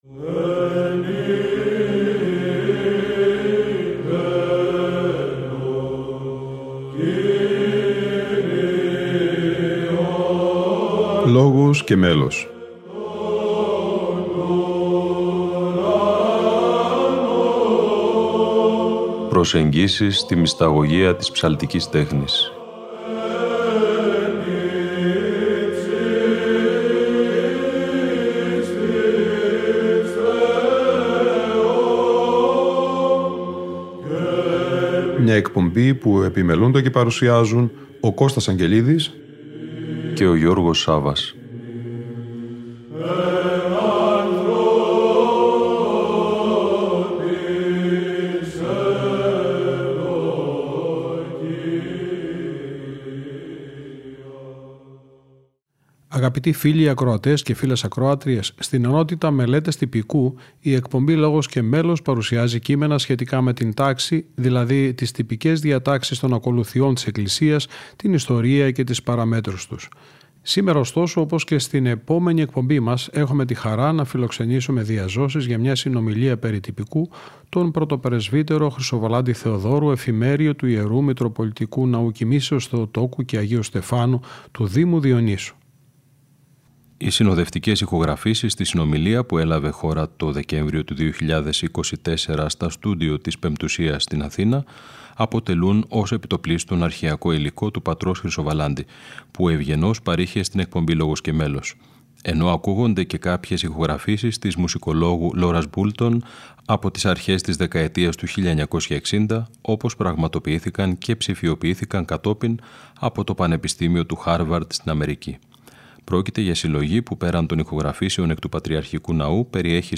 Μελέτες Τυπικού - Μια συνομιλία